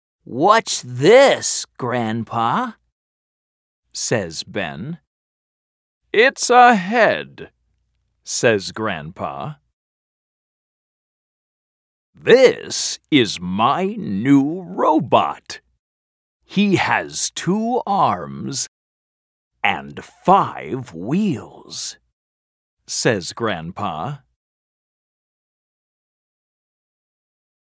Track 2 Hello, Clunk US English.mp3